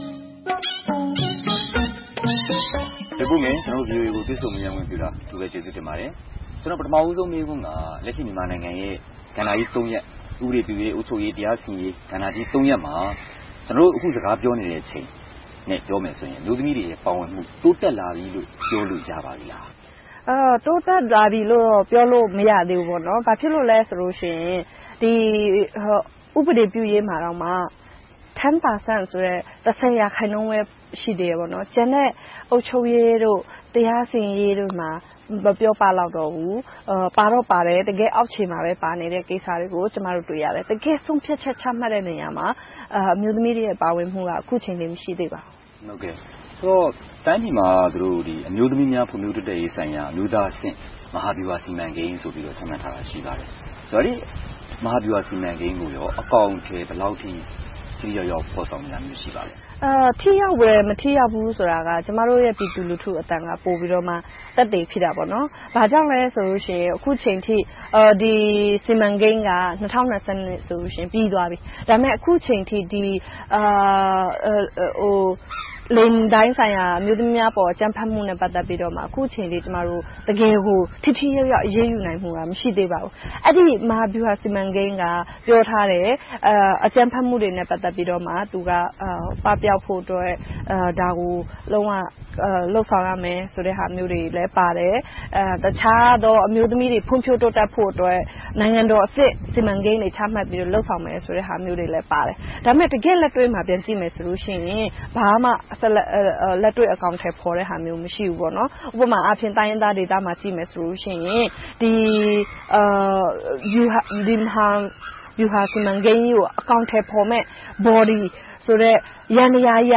တွေ့ဆုံမေးမြန်းထားပါတယ်။